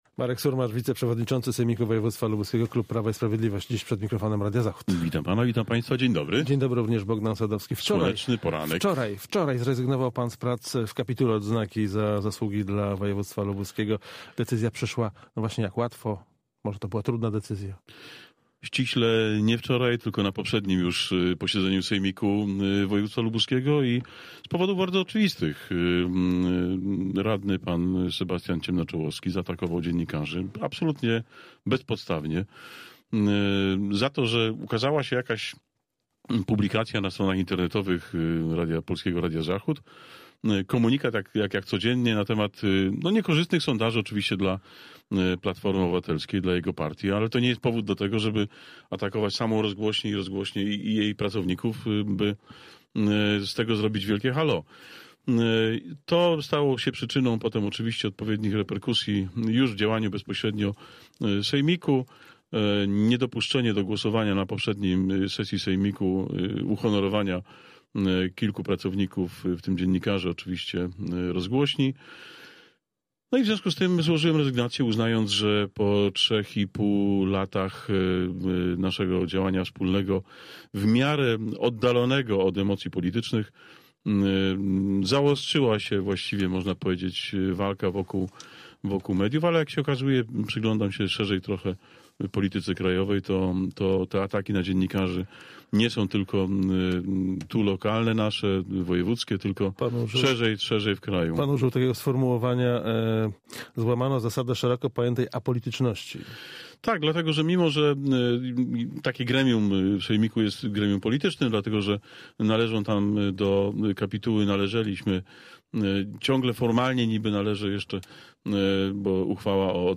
Z wiceprzewodniczącym sejmiku wojewódzkiego (PiS) rozmawia